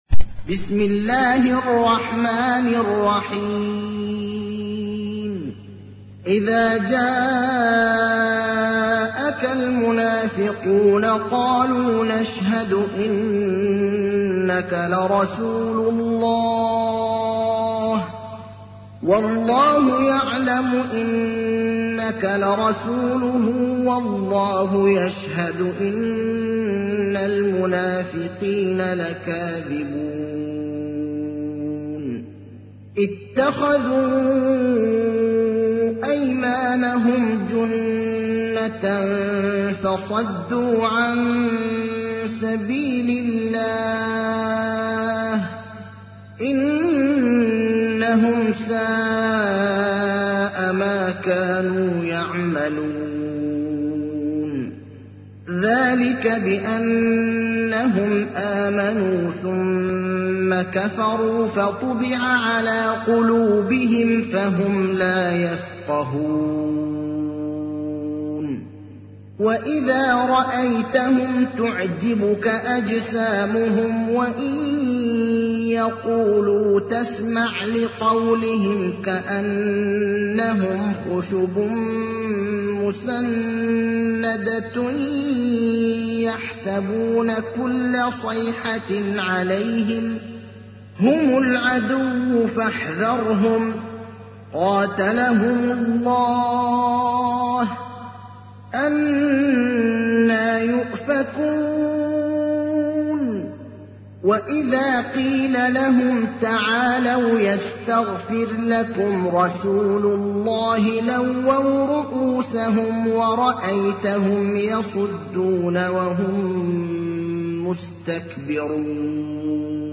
تحميل : 63. سورة المنافقون / القارئ ابراهيم الأخضر / القرآن الكريم / موقع يا حسين